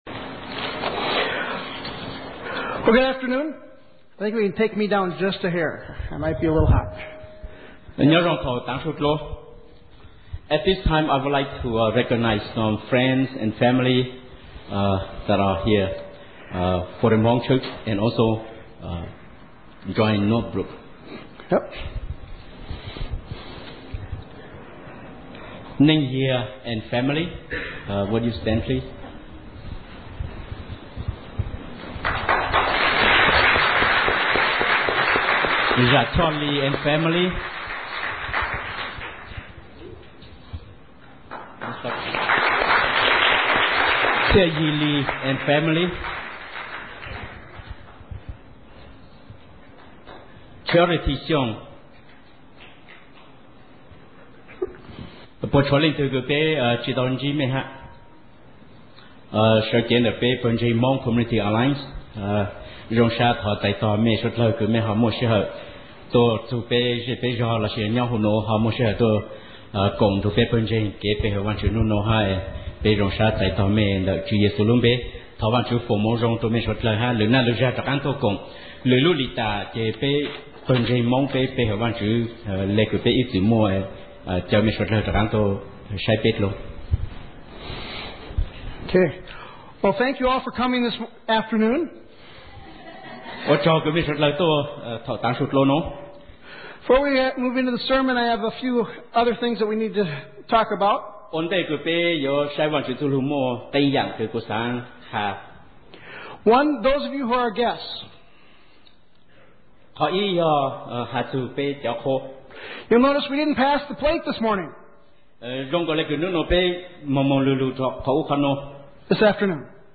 NBAC/HCAC Joint Thanksgiving Service